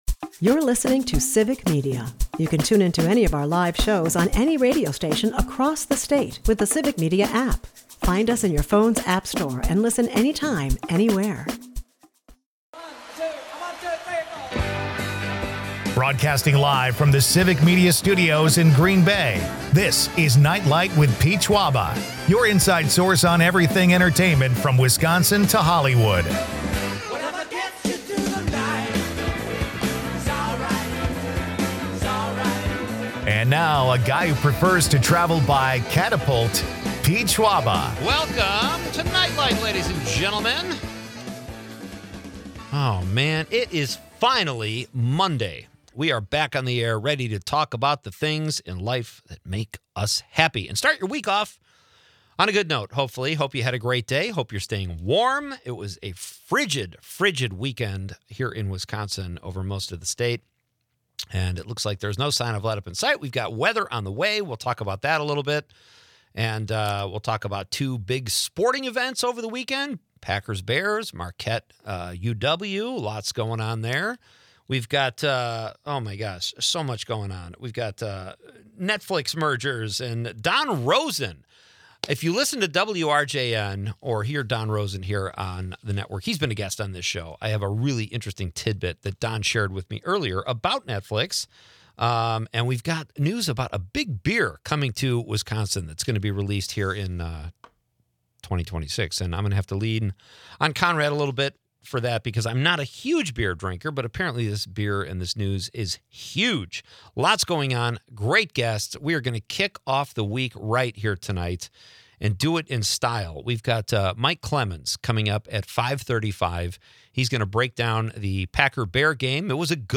The show also dives into favorite football movies, with listeners chiming in on classics like 'Rudy' and 'The Longest Yard.'